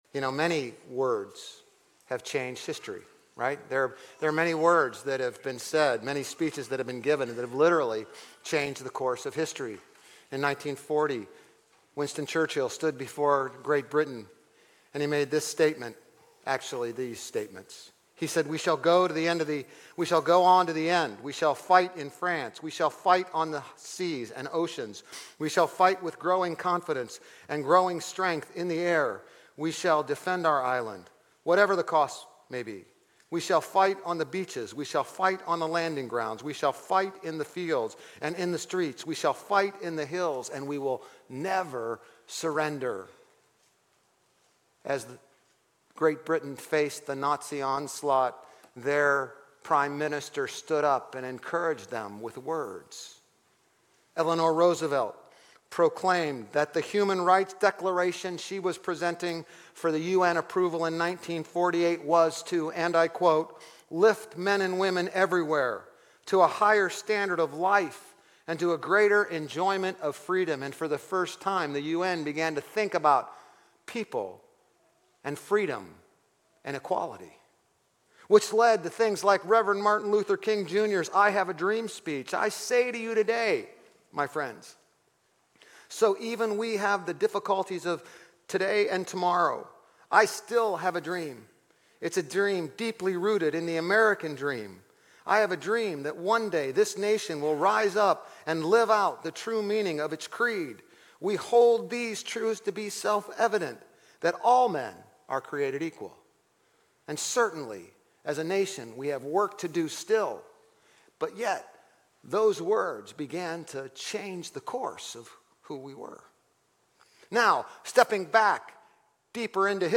GCC-OJ-July-9-Sermon.mp3